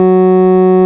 Index of /90_sSampleCDs/Keyboards of The 60's and 70's - CD1/ORG_FarfisaCombo/ORG_FarfisaCombo
ORG_VIP Pwr2 F#3.wav